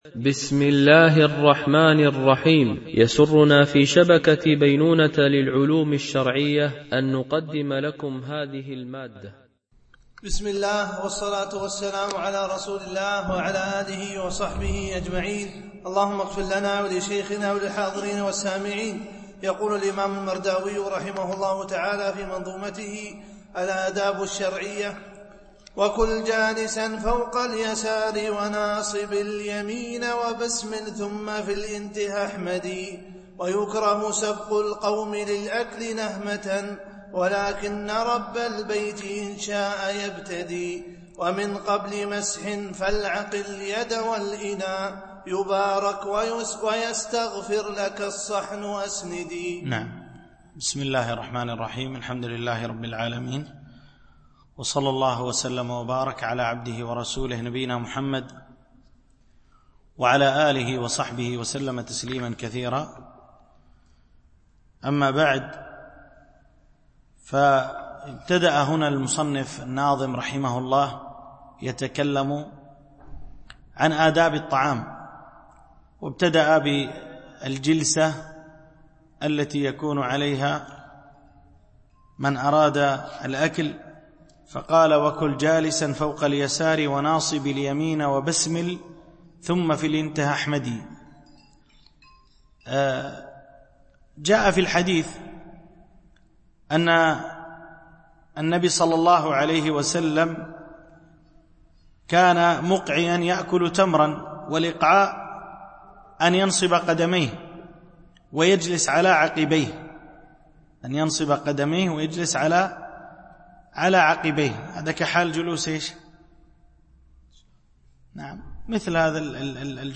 شرح منظومة الآداب الشرعية – الدرس22 ( الأبيات 304-326 )